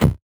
CS_VocoBitB_Hit-14.wav